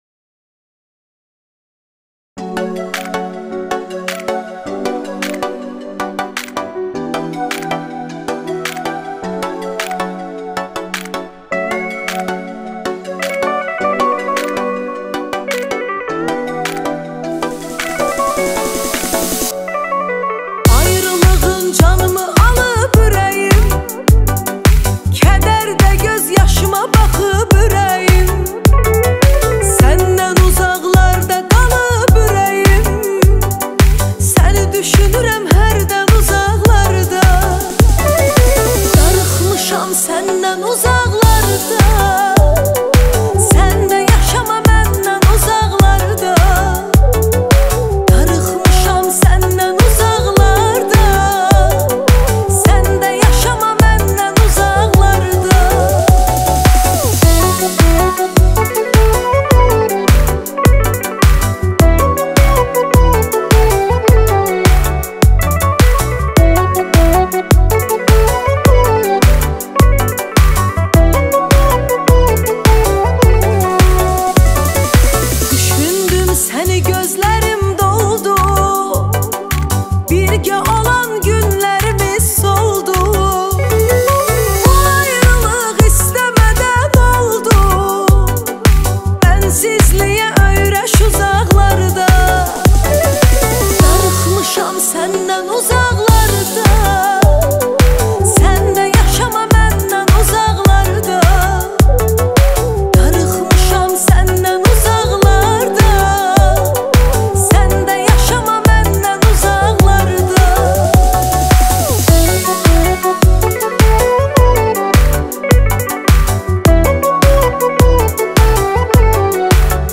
موزیک آذربایجانی